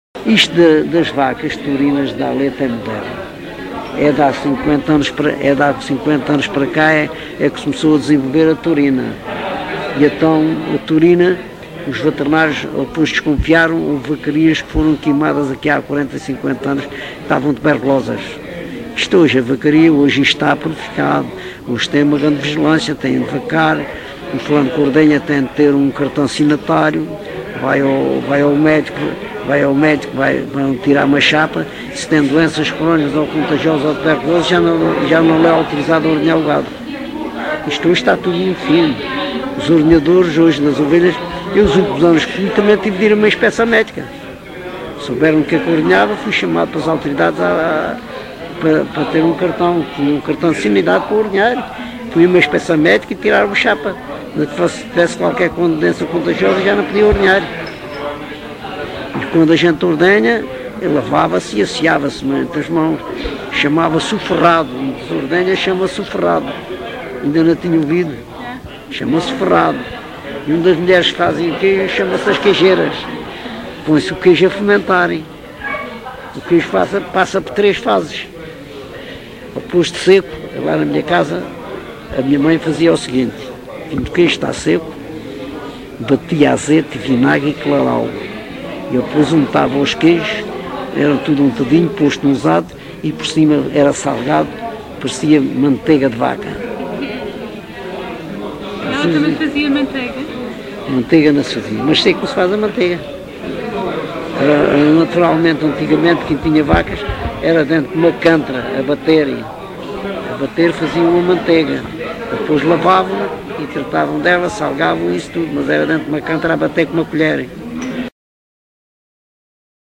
LocalidadeCouço (Coruche, Santarém)